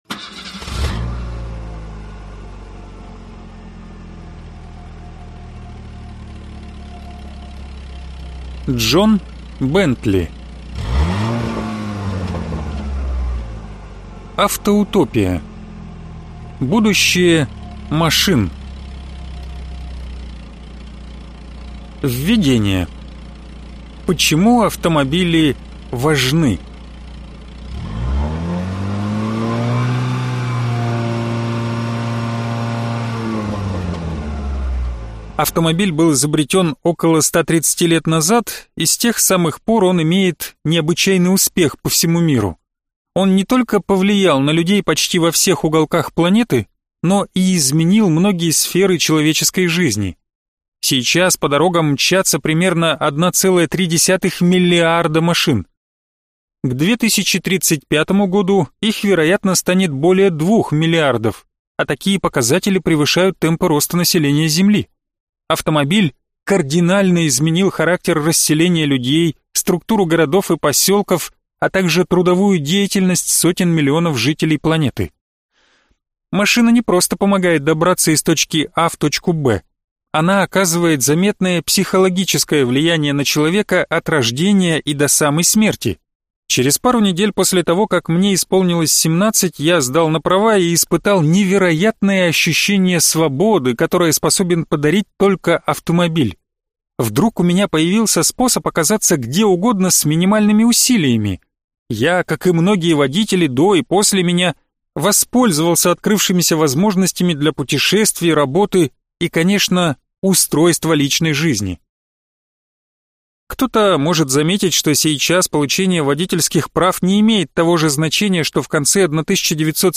Аудиокнига Автоутопия. Будущее машин | Библиотека аудиокниг